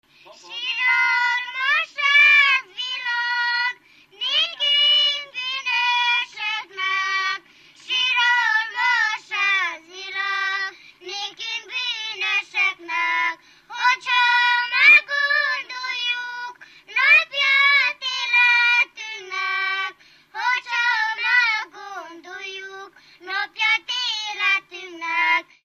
Moldva és Bukovina - Moldva - Lujzikalagor
Stílus: 5. Rákóczi dallamkör és fríg környezete
Szótagszám: 7.6.7.6
Kadencia: 5 (b3) 2 1